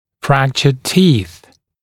[‘frækʧəd tiːθ][‘фрэкчэд ти:с]зубы, имеющие или имевшие трещны и переломы